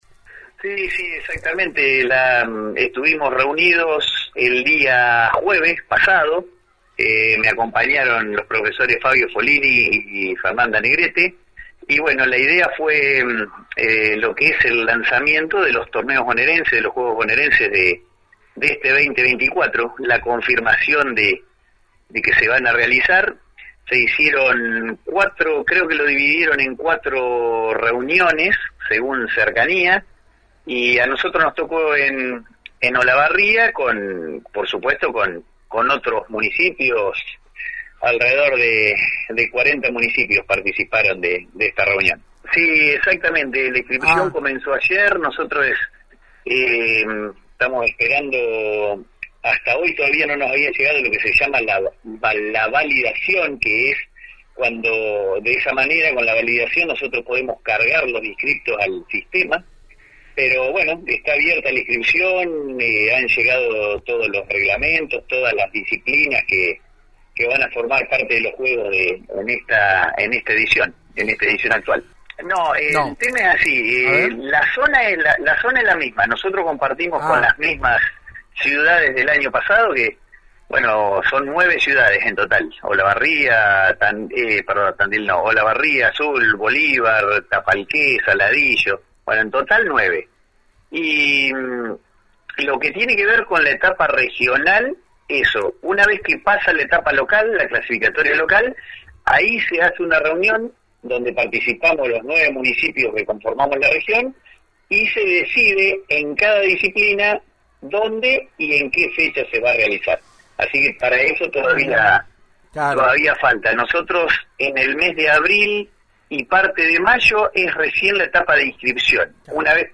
Así lo confirmó este martes a la 91.5 el secretario de Deportes y Cultura local, Prof. Fernando Muñiz, luego de la reunión informativa que tuvo lugar días pasados en Olavarría donde acudieron representantes de la región a la que pertenece Las Flores.